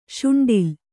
♪ śuṇḍil